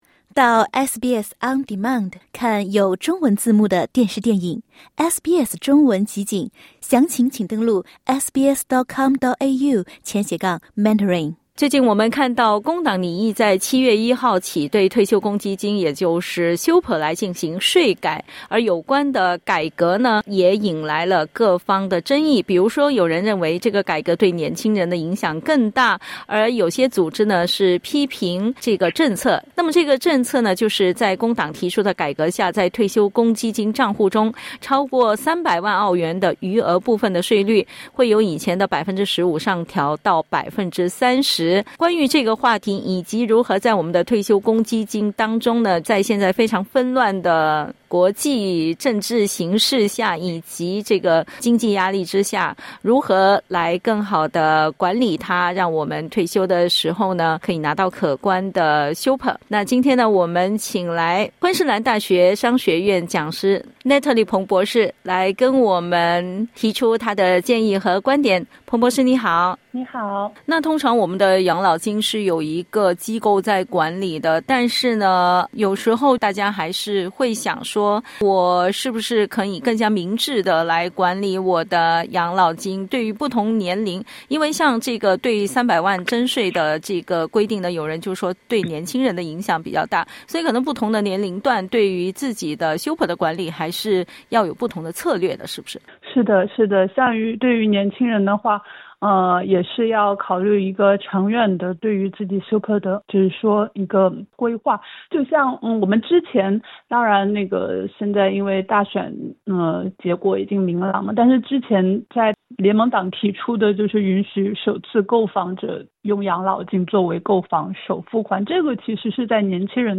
（点击音频收听详细采访） 澳洲7月起在退休公积金（Super）方面将有一些新变化，雇主必须为员工缴纳的最低退休公积金保证比例，将从11.5%提高到12%。